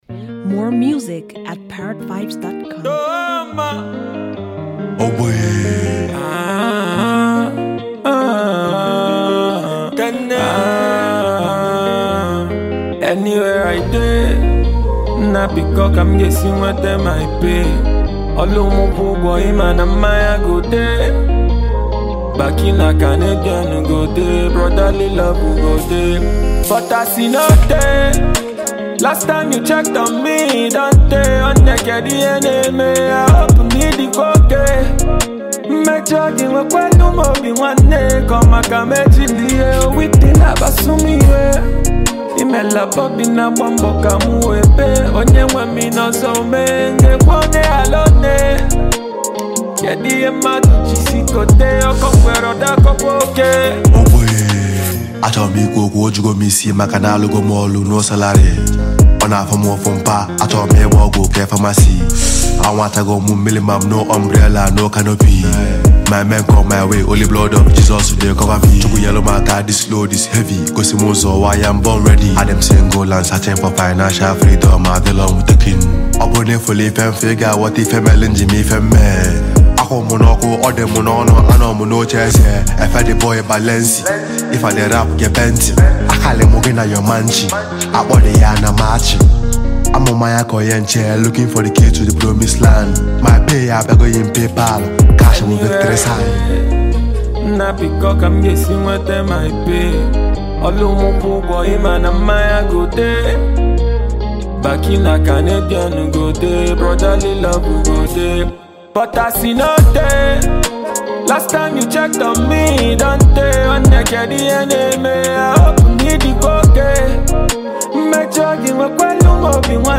indigenous rap